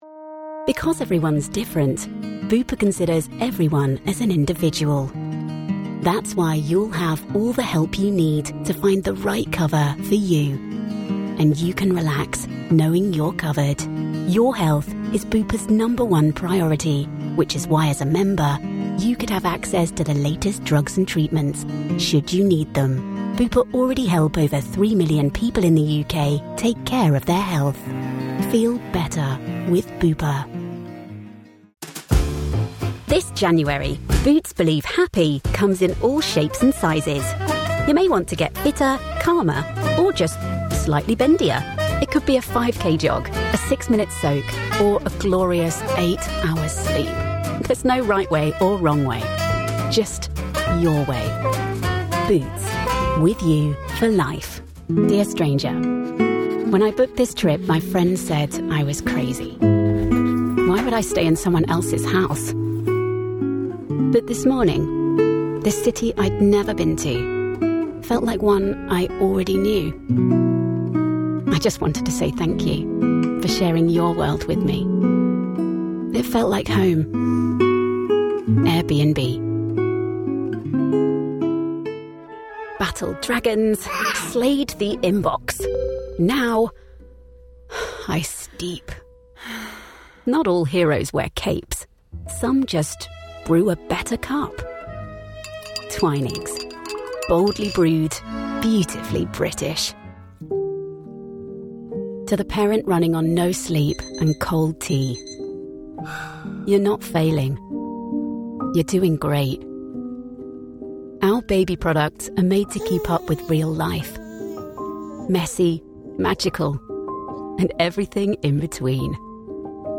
Englisch (Britisch)
Natürlich, Vielseitig, Freundlich, Warm, Corporate